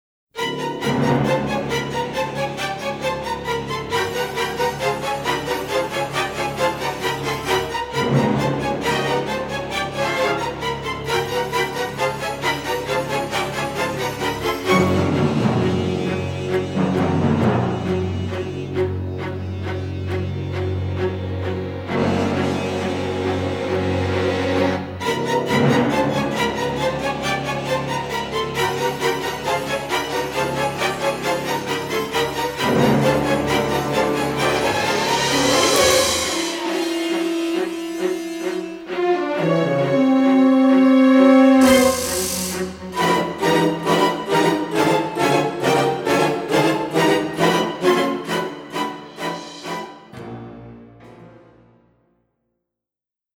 Orchestral Film Version